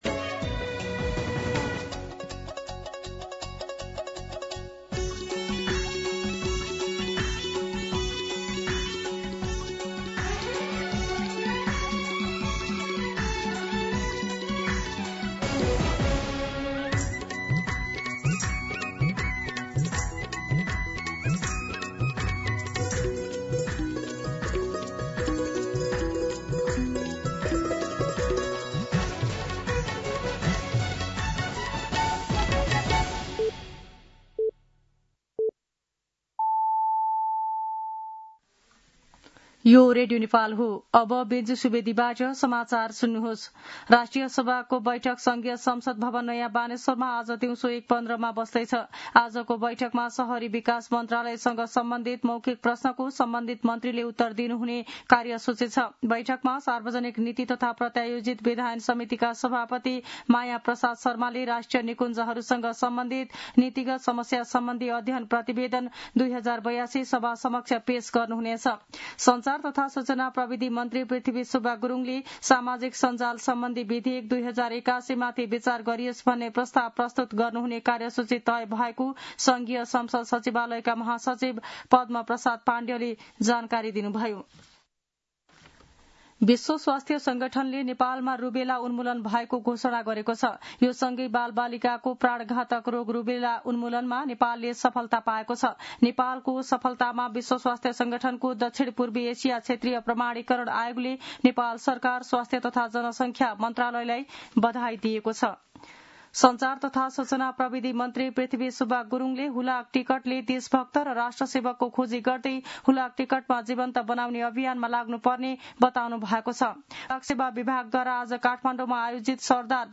दिउँसो १ बजेको नेपाली समाचार : ३ भदौ , २०८२
1pm-News-03.mp3